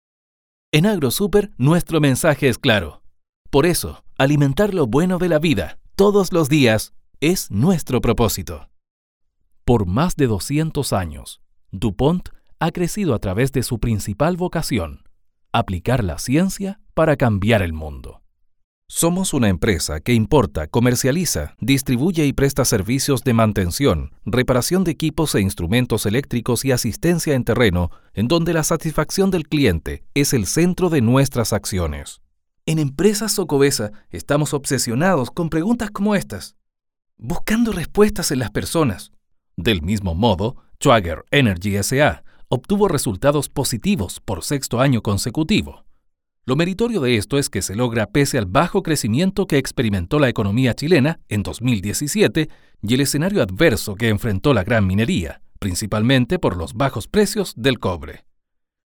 Locutor Video Corporativo
Dare énfasis en tu mensaje para captar la atención del cliente, donde la voz debe reflejar tu identidad de marca para que el mensaje llegue de la mejor forma.
• Sala Acústica para grabaciones limpias de ruidos.
demo-mix-locutorcorporativo.mp3